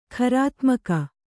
♪ kharātmaka